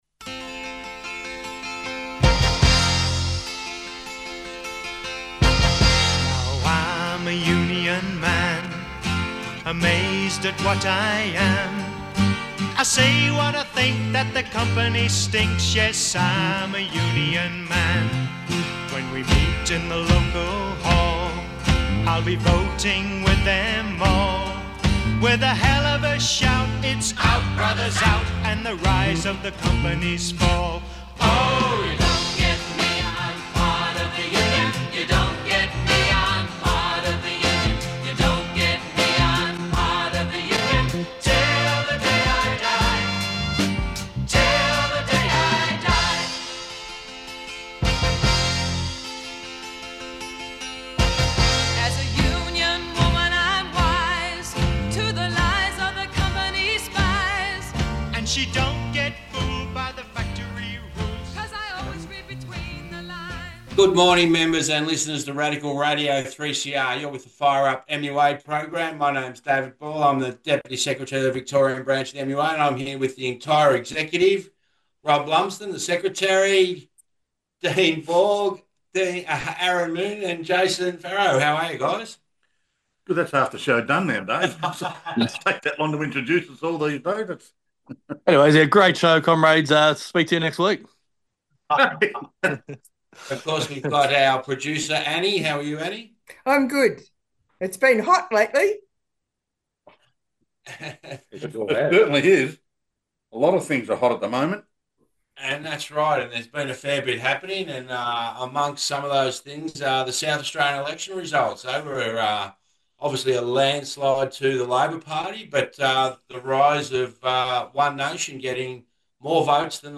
The full team in today. A discussion about the South Australian elections and recent visit to Canberra about automation.